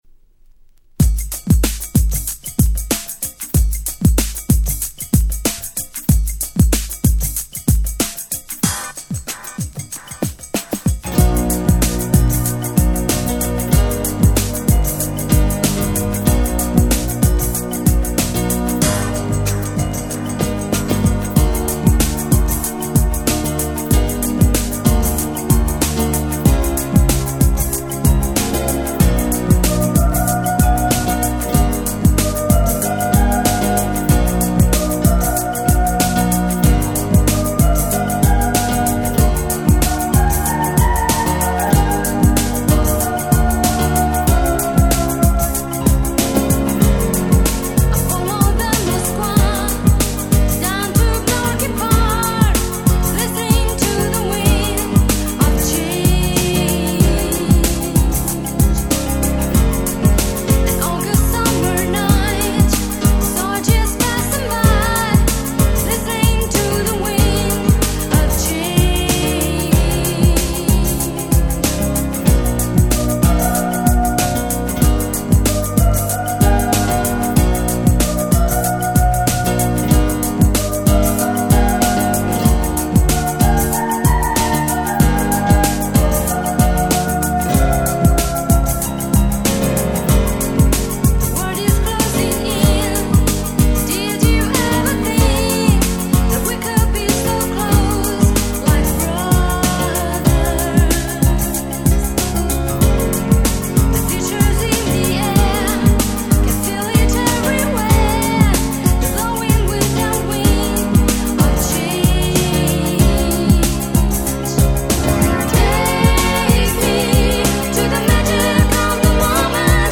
【Media】Vinyl 12'' Single
931 Super Nice Ground Beat !!
※後半のPianoのパートがマジで最高です！！